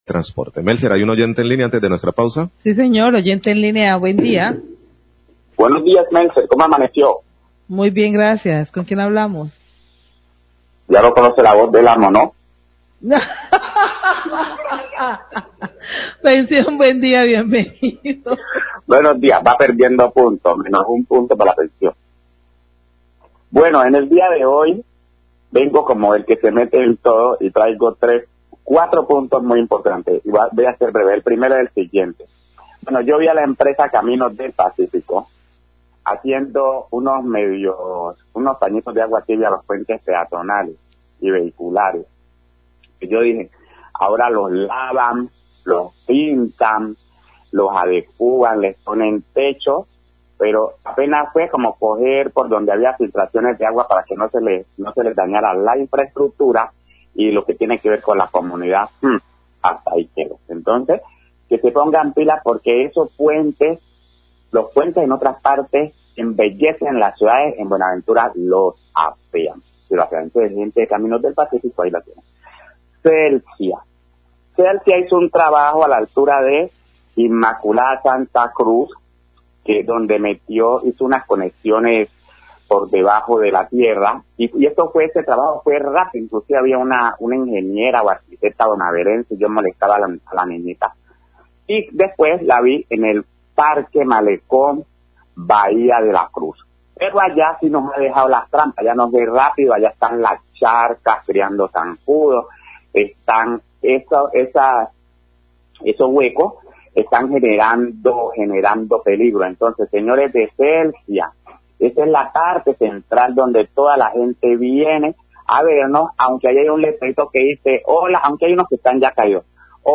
Radio
Oyente destaca la rapidez del trabajo de subterranización de redes de energía en un tramo entre el barrio La Inmaculada y Santa Cruz pero critica que el mismo tipo de trabajo en el parque malecón Bahía de la Cruz, se ha demorado y se han dejado abiertos huecos en una zona turística como esta.